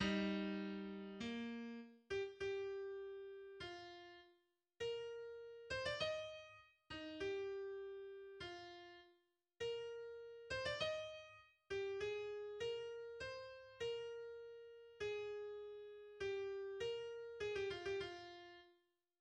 El segundo movimiento, Andante moderato, está en mi bemol mayor y en compás de 2/4. El movimiento lento se cierra con una recapitulación en "imagen especular", en la que el tema secundario aparece primero y se resuelve en la tónica antes de la aparición del tema principal.